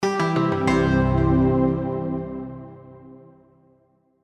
Victory SoundFX5.wav